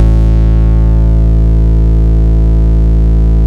RAZOR BASS-R.wav